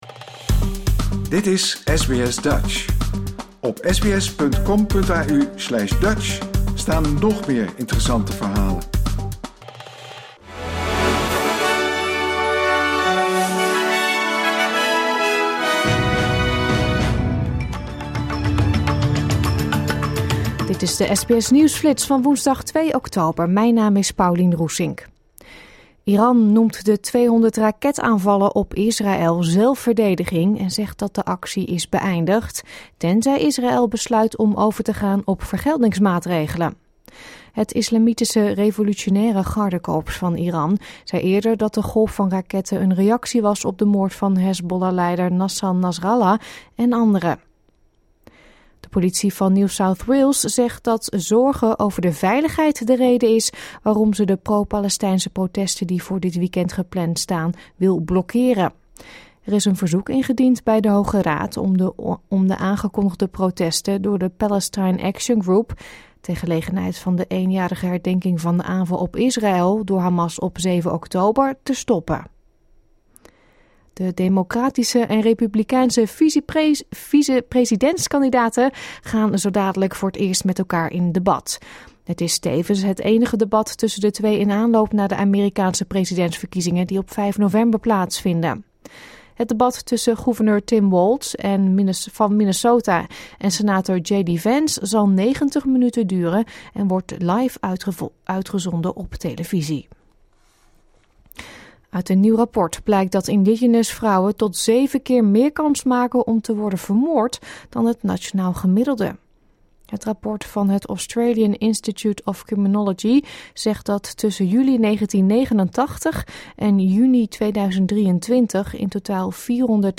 Dit is de SBS Nieuwsflits van woensdagochtend 2 oktober 2024.